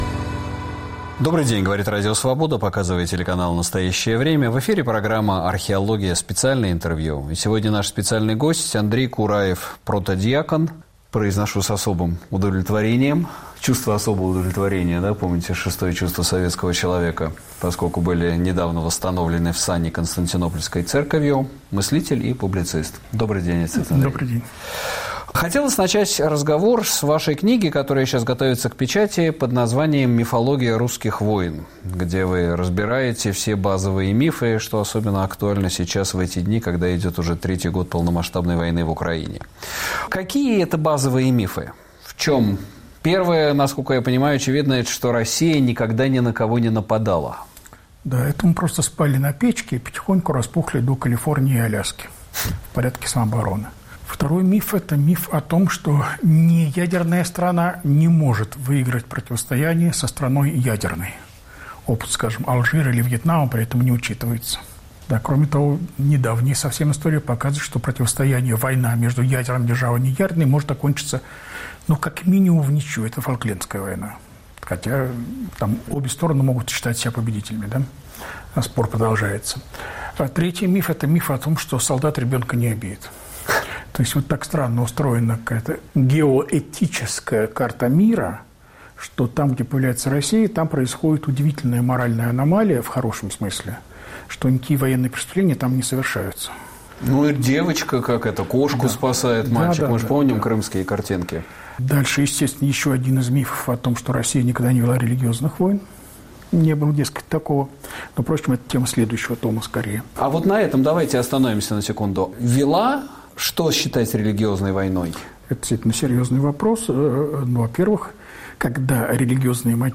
На вопросы Сергея Медведева отвечает протодьякон, мыслитель и публицист Андрей Кураев